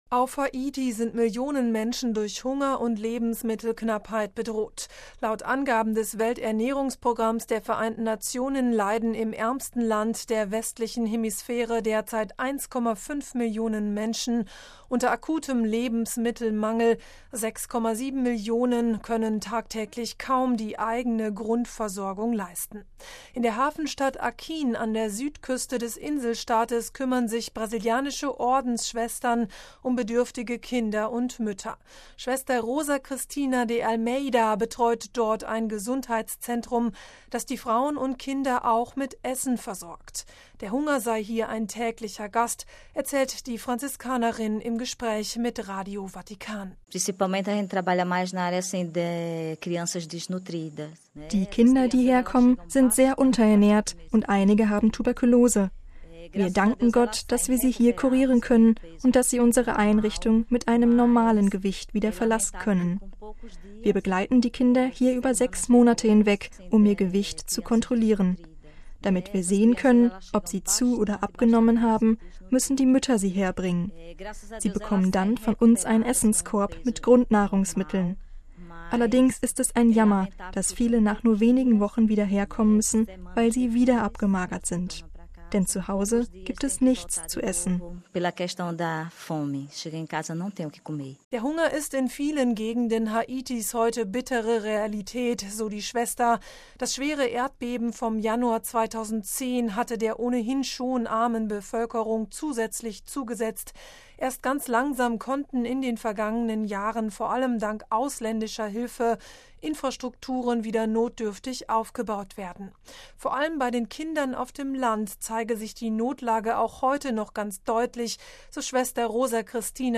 Der Hunger sei hier ein täglicher Gast, erzählt die Franziskanerin im Gespräch mit Radio Vatikan: